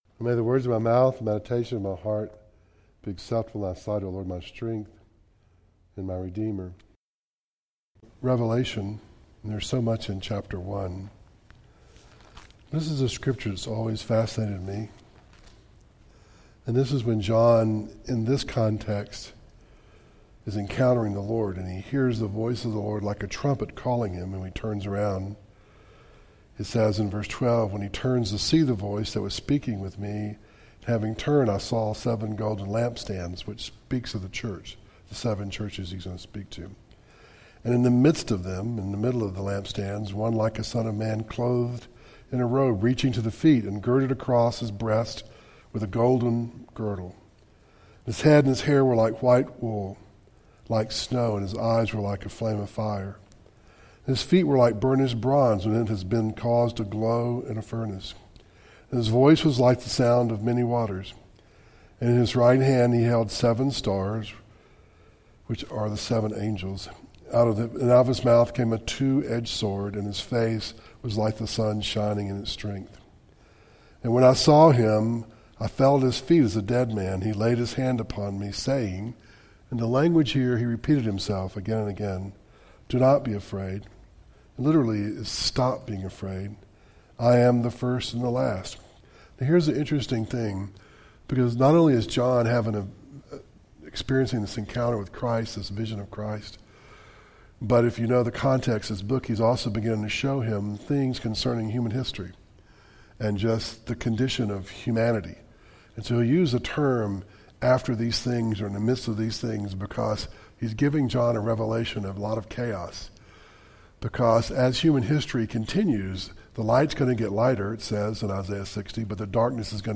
Series: Audio Devotionals